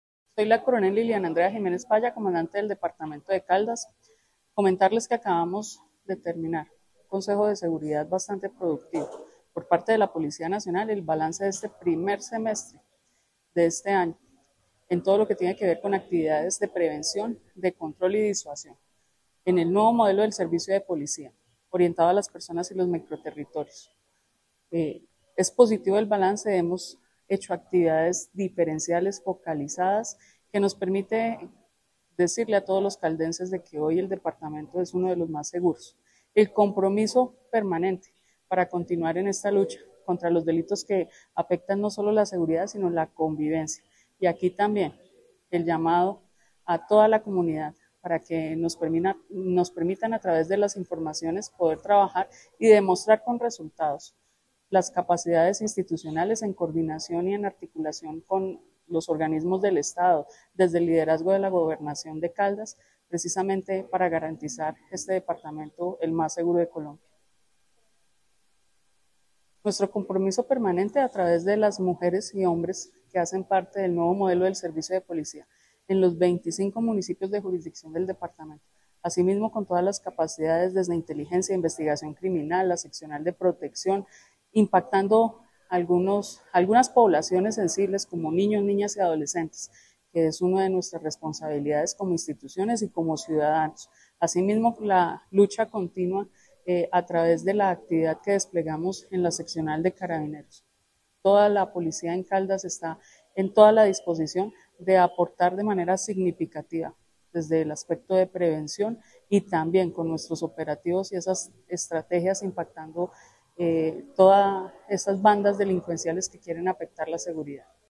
Coronel de la Policía Departamental, Liliana Andrea Jiménez.
Full-Coronel-Liliana-Andrea-Jimenez-Falla-comandante-Policia-Caldas.mp3